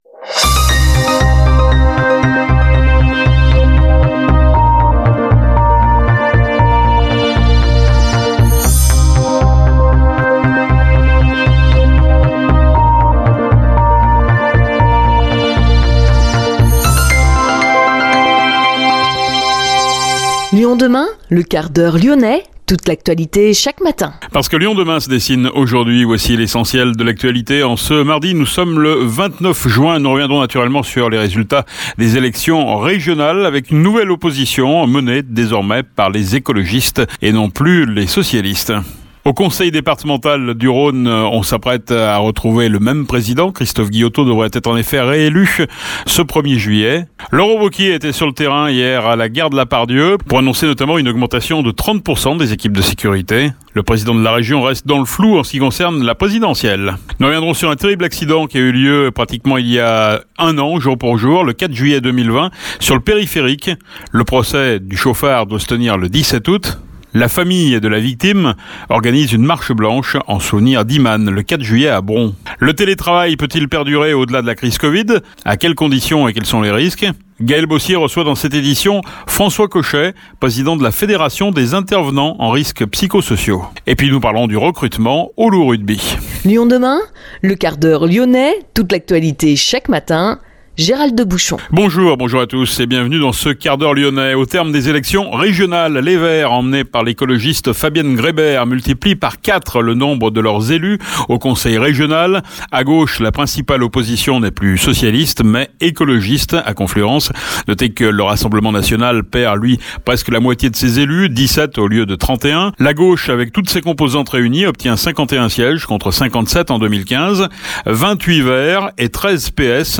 Le Quart-d’Heure Lyonnais est aussi diffusé en FM sur Pluriel 91,5, Salam 91,1, Judaïca 94,5 et Arménie 102,6 Partager :